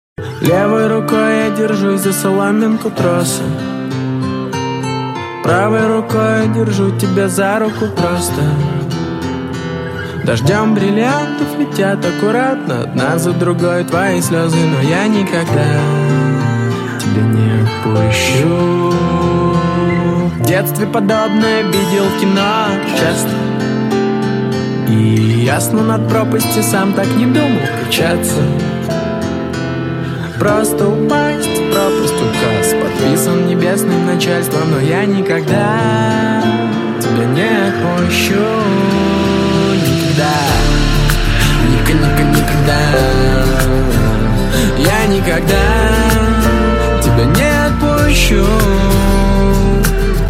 • Качество: 320, Stereo
гитара
мужской вокал